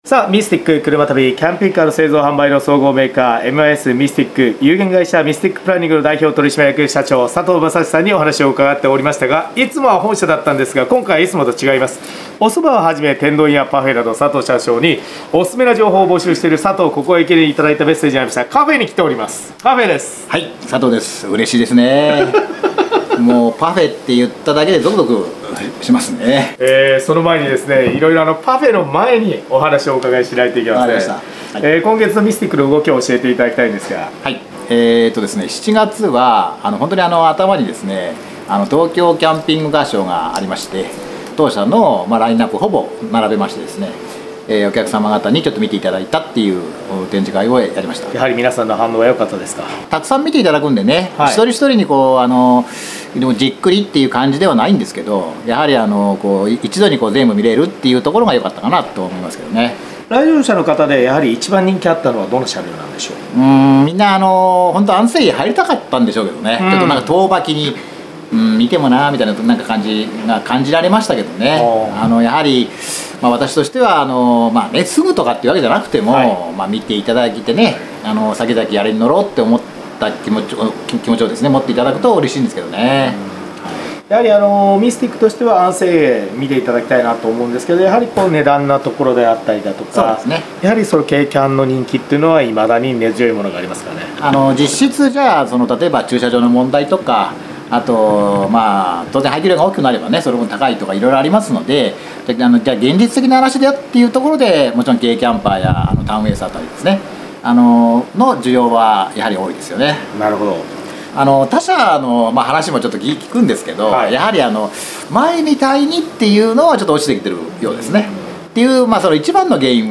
毎週土曜午前11時からの生放送。